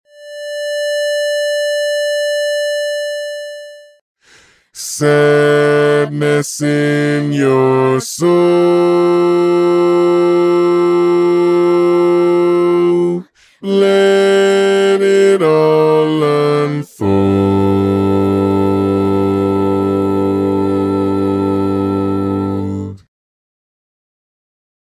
Key written in: D Minor
Learning tracks sung by